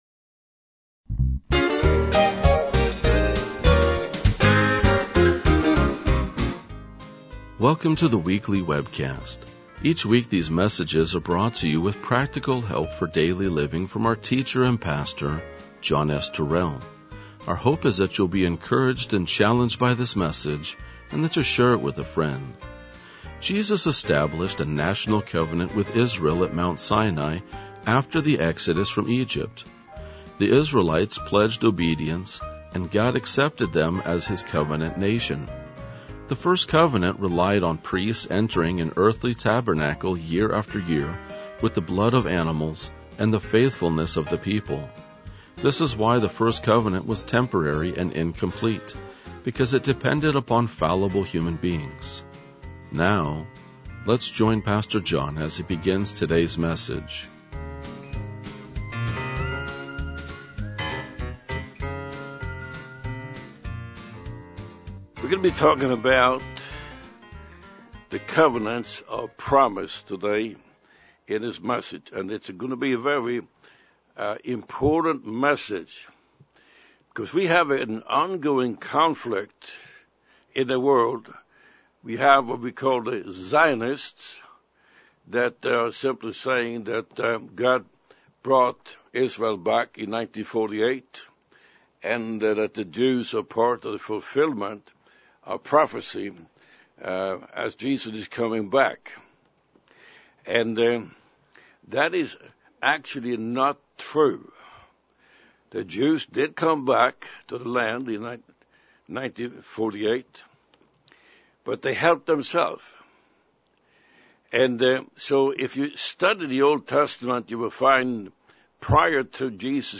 RLJ-2041-Sermon.mp3